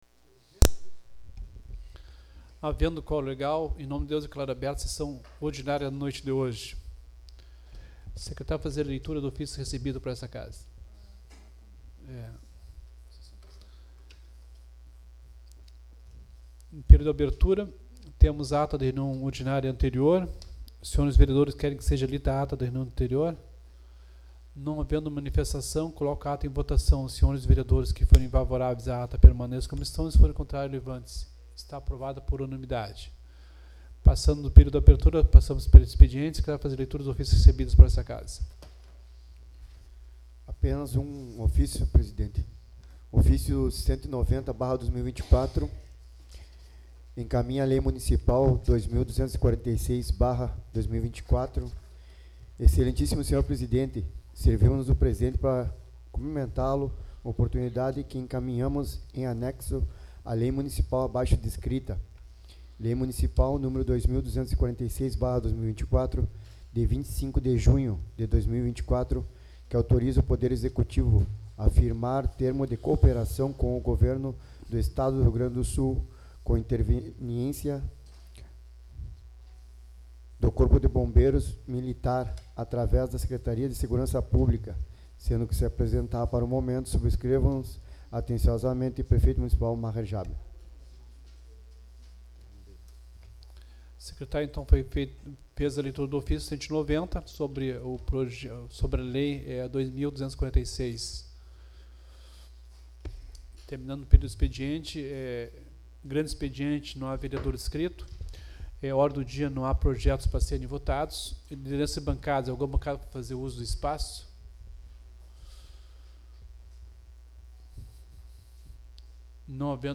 Sessão Ordinária 01/07/2024